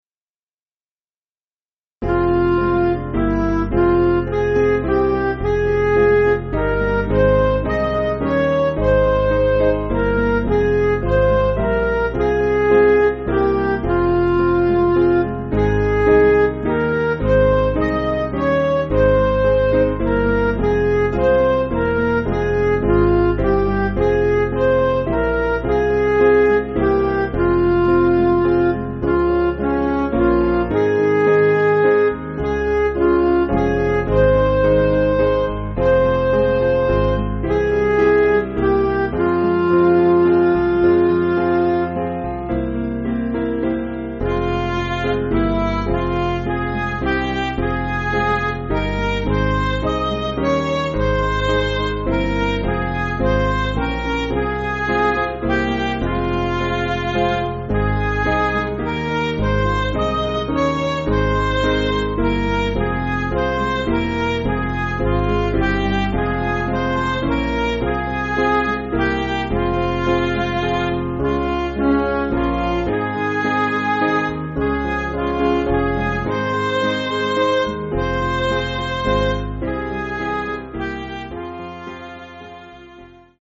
Piano & Instrumental
(CM)   5/Fm